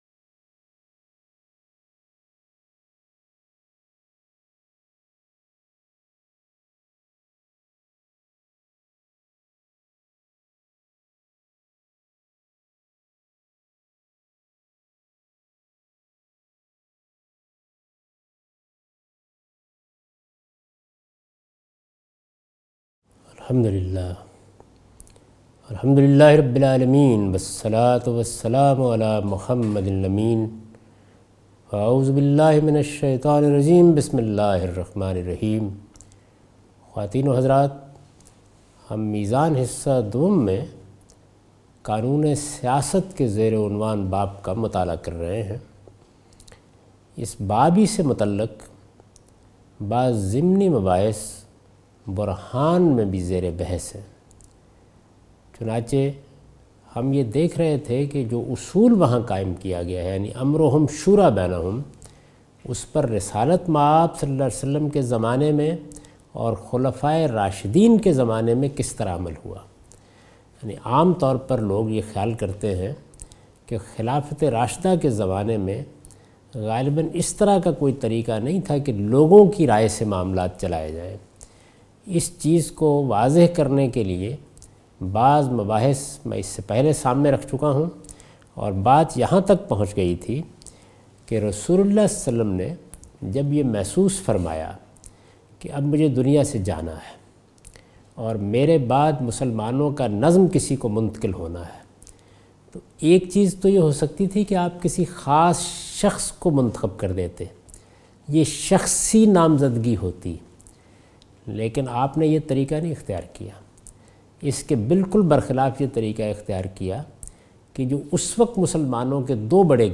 A comprehensive course on Islam, wherein Javed Ahmad Ghamidi teaches his book ‘Meezan’.
In this lecture he teaches the topic 'The Political Shari'ah' from 2nd part of his book. This sitting contains discussion on how government will be run in an Islamic socitey according to Quran and Sunnah.